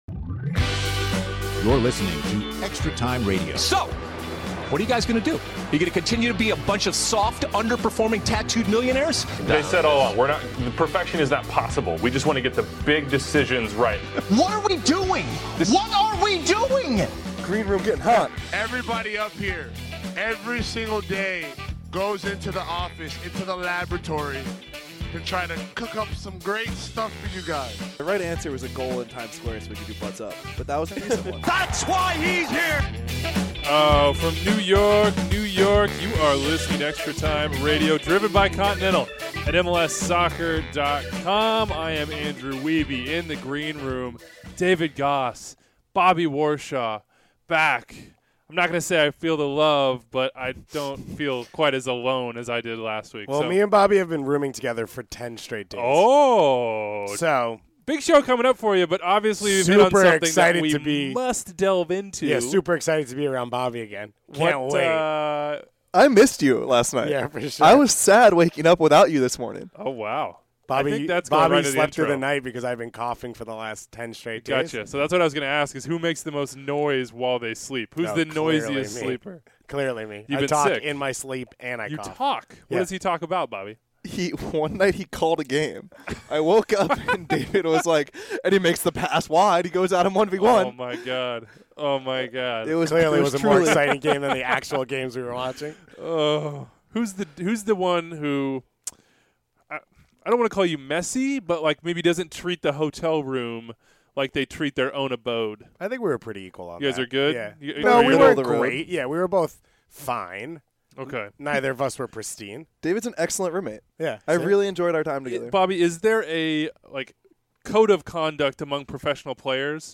Ashley Cole interview